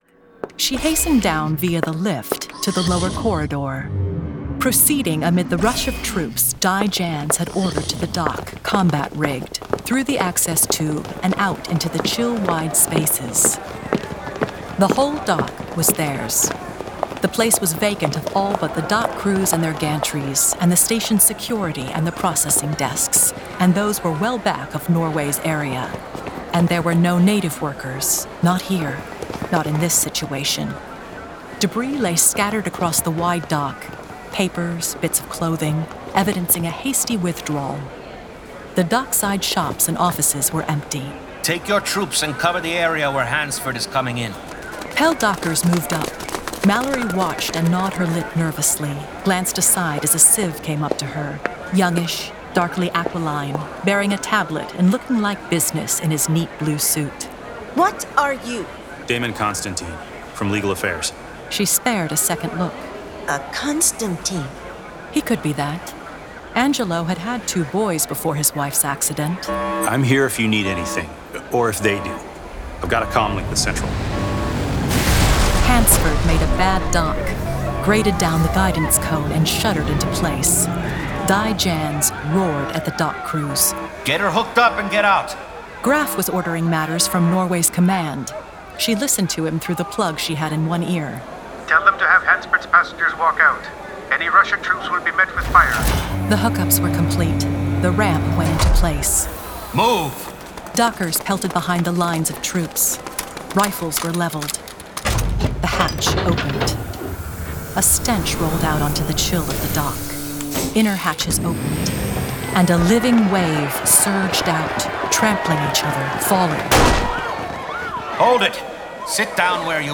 Full Cast. Cinematic Music. Sound Effects.
[Dramatized Adaptation]
The Hugo Award-winning classic sci-fi novel, brought to life with a full cast, sound effects and cinematic music!